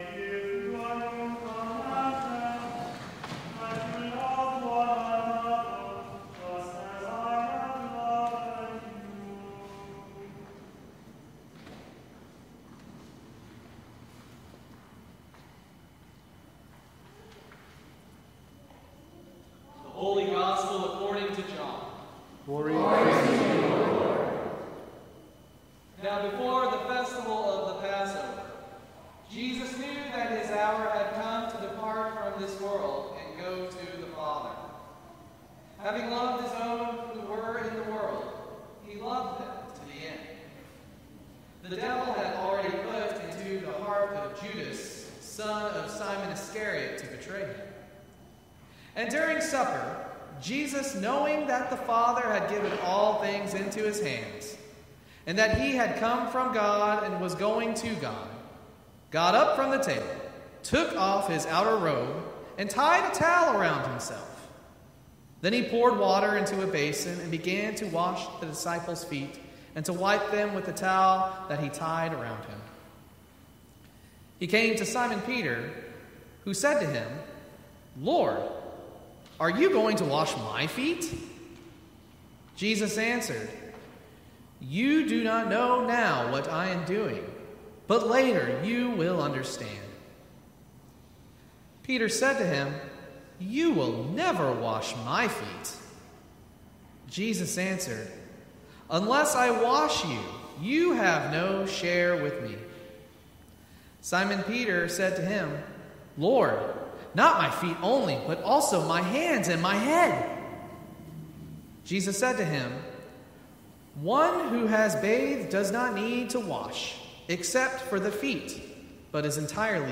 Maundy Thursday Sermon